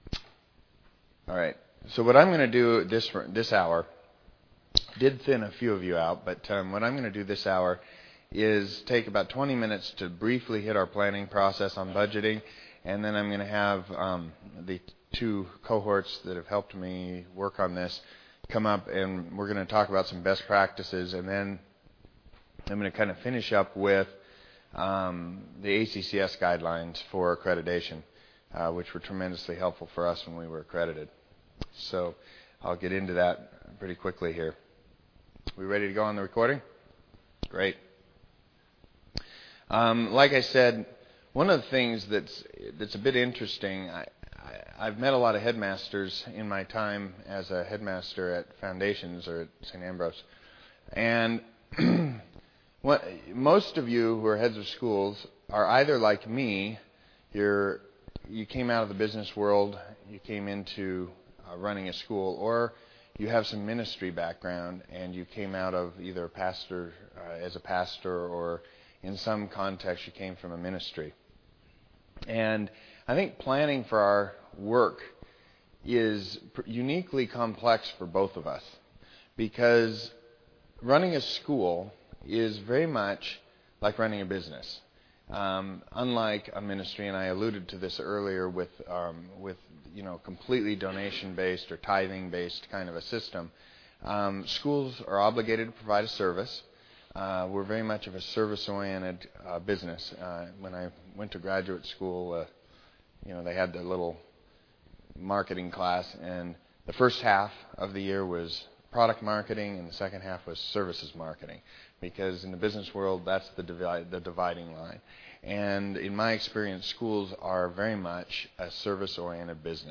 2009 Workshop Talk | 0:55:24 | Budgets & Finance
The Association of Classical & Christian Schools presents Repairing the Ruins, the ACCS annual conference, copyright ACCS.